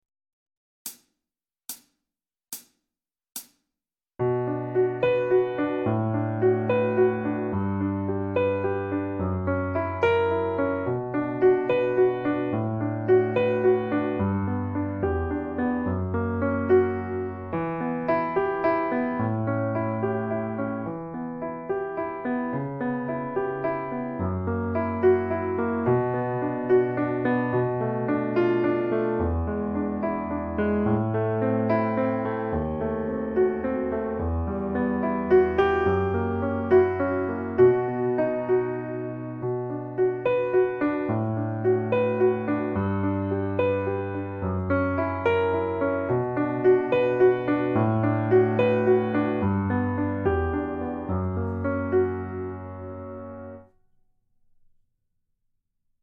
lecture chantée - complet